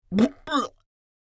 Here you’ll Find realistic Vomiting Sounds with many variations for Male, Female and Children!
Female-old-woman-retching-2.mp3